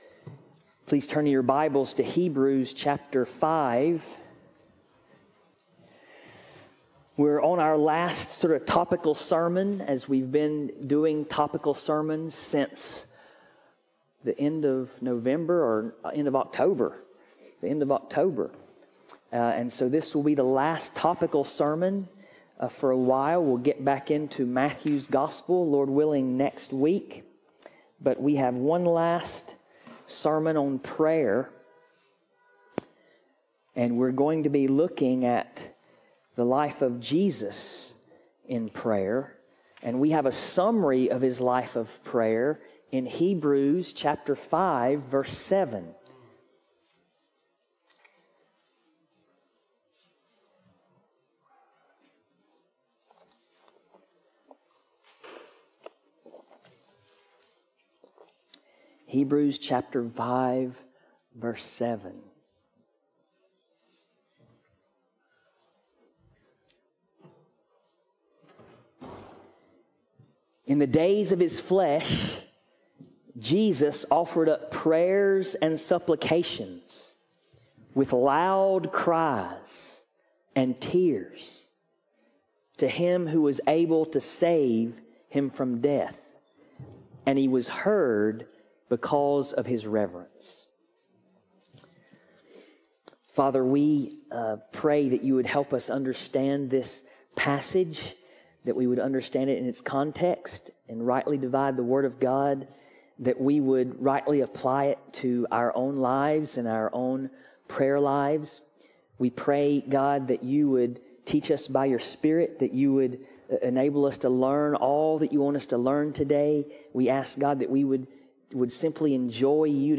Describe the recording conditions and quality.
Passage: Hebrews 5:7 Service Type: Sunday Morning